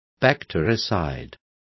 Complete with pronunciation of the translation of bactericides.